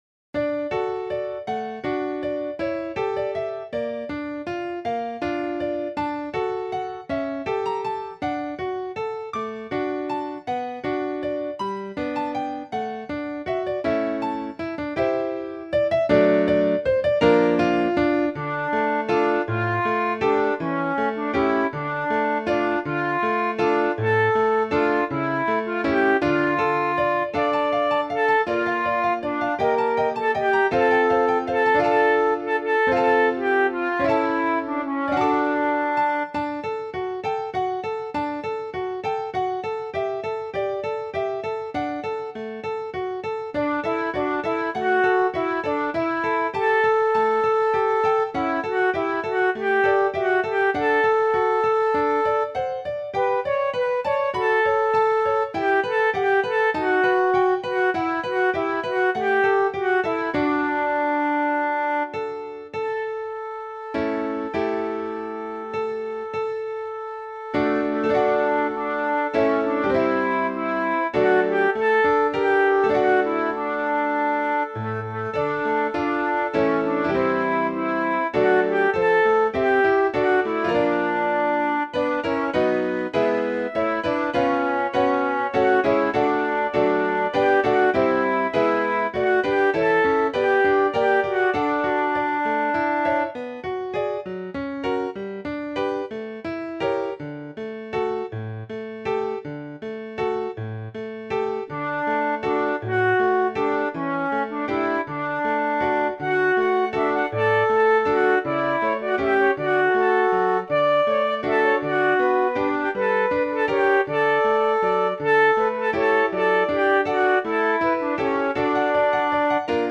complex arrangement of five beloved Christmas primary songs
Voicing/Instrumentation: SAT , Primary Children/Primary Solo
Choir with Soloist or Optional Soloist
Medley